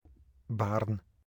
kuuntele ääntämys (ohje)) on kunta ja kaupunki Alankomaissa Utrechtin provinssissa.